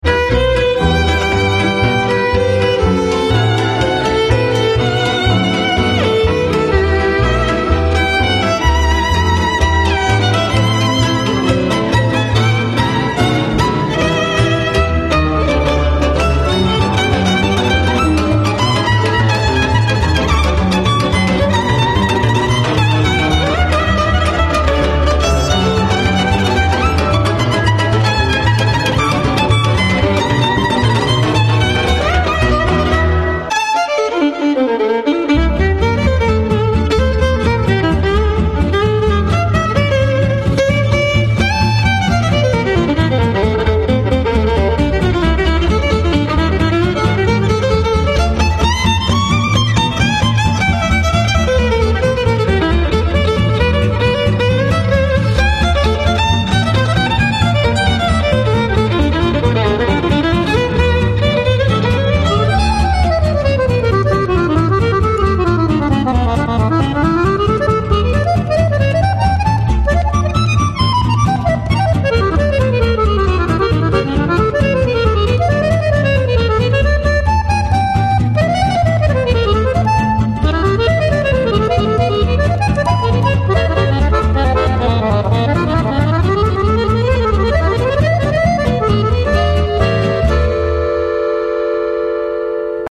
medley_tzigane.mp3